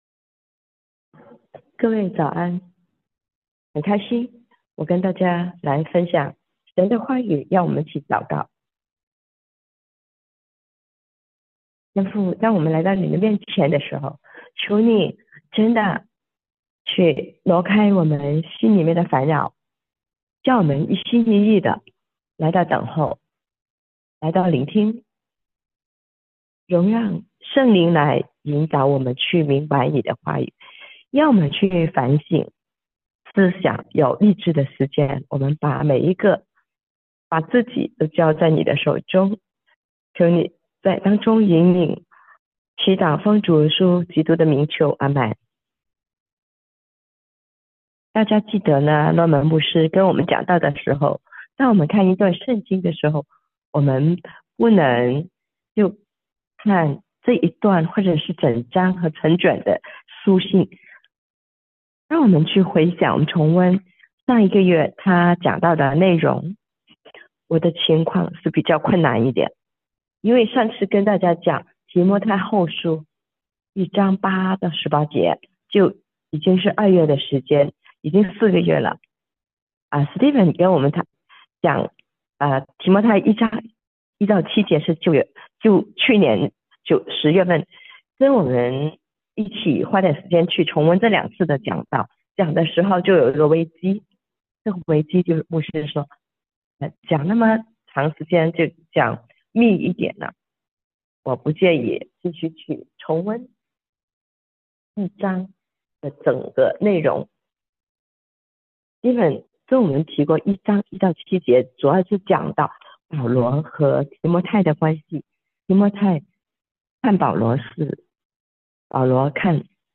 我兒，忠於所託 – 普通話傳譯